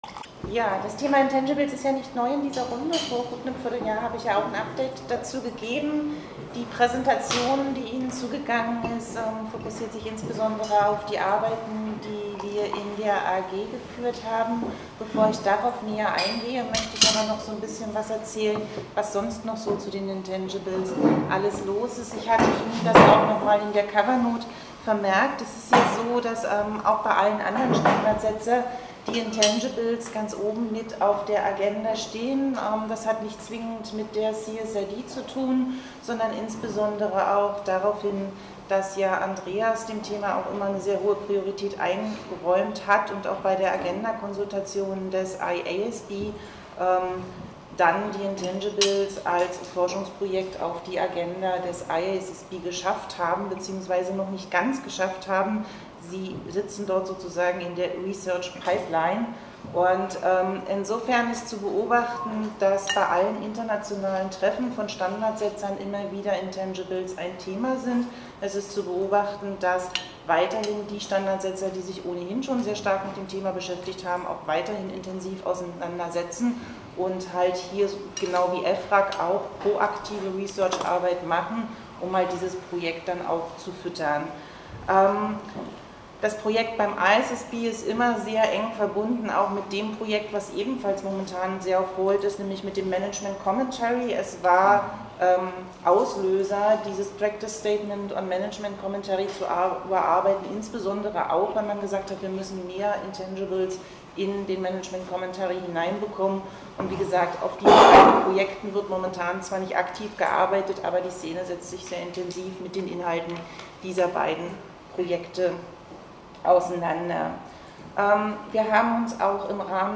33. Sitzung Gemeinsamer Fachausschuss • DRSC Website